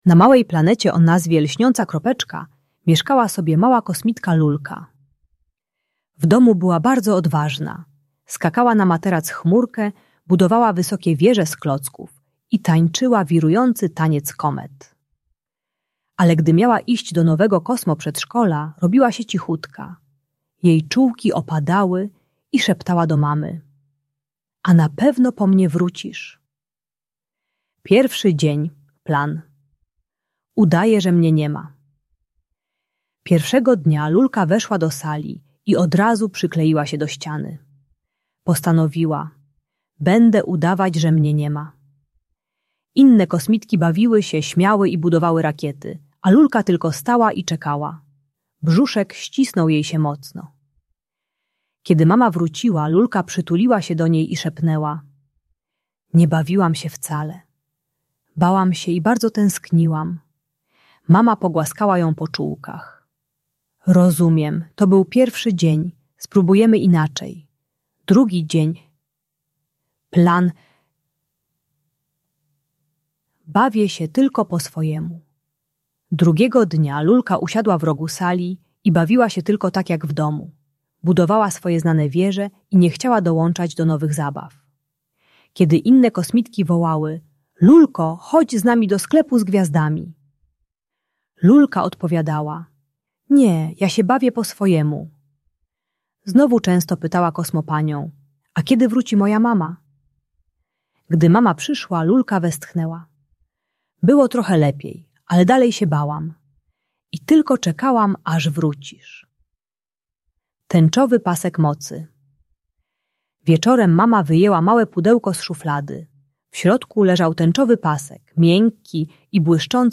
Bajka o lęku separacyjnym dla dzieci 3-4 lata, które płaczą przy rozstaniu z mamą w przedszkolu. Ta audiobajka o adaptacji w przedszkolu pomaga dziecku zrozumieć, że mama zawsze wraca.